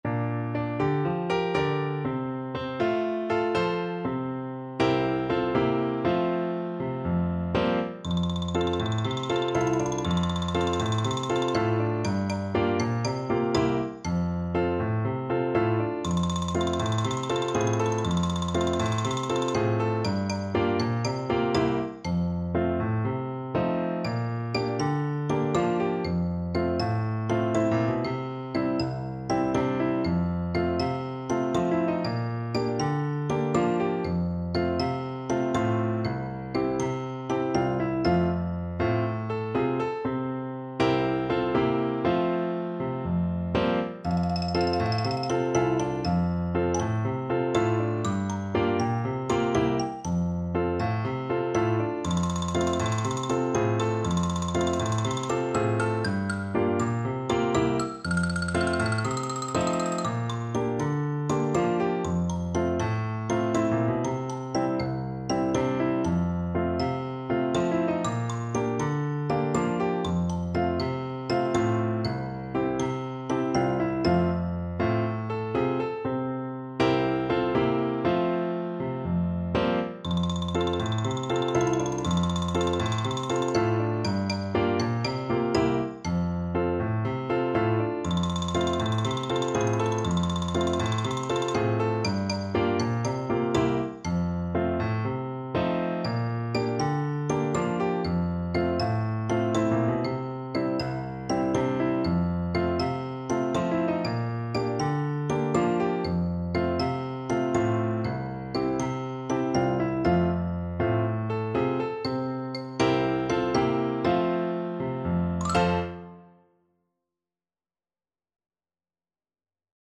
Orchestral Percussion
Xylophone
4/4 (View more 4/4 Music)
Calypso = 120
World (View more World Percussion Music)